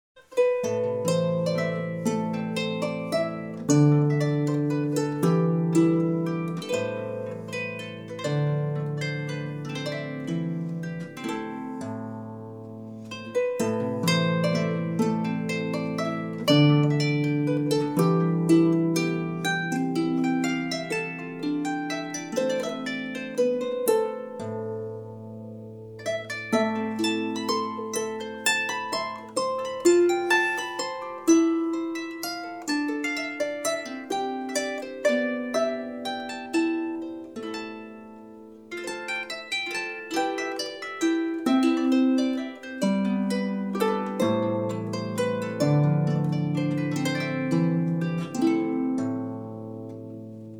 The sessions were very relaxed, aside from the time the recording gear was picking up a Mexican radio station thru one of the channels.
All are instrumental pieces, so there are no pages here devoted to individual song lyrics.
This is a great disk, especially if you're looking for background music to a romantic dinner or for meditation, and is now available for only $15 in our link Online Disk Store